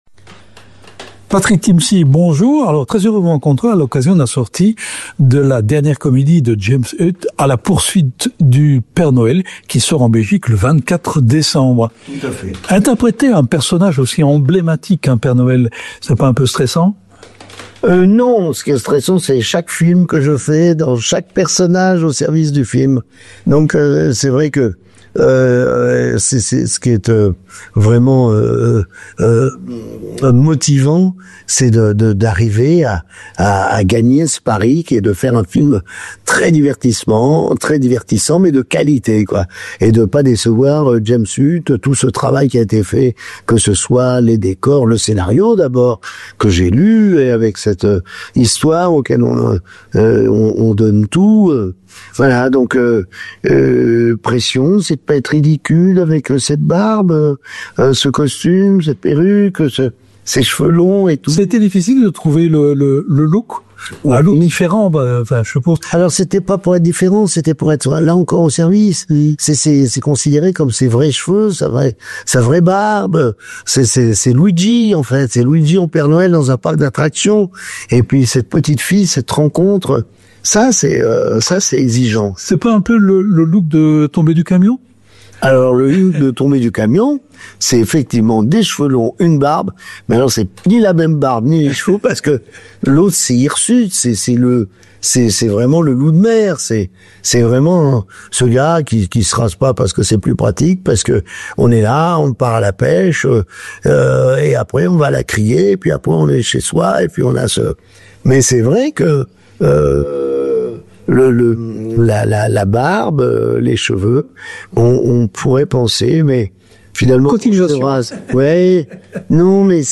Rencontre pleine d’émotions avec le comédien.«